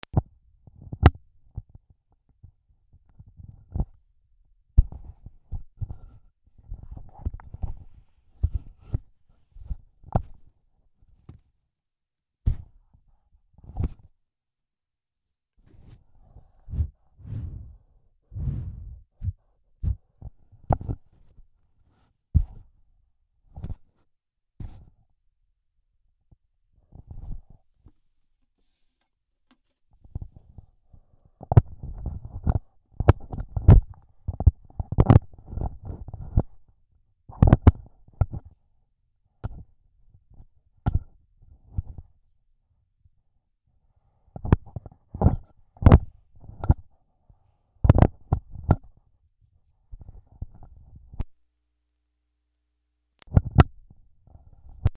マイクごそごそ
/ M｜他分類 / L10 ｜電化製品・機械